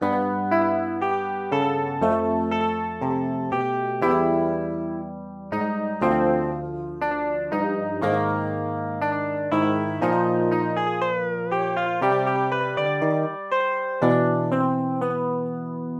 Tag: 120 bpm LoFi Loops Guitar Electric Loops 2.69 MB wav Key : B Ableton Live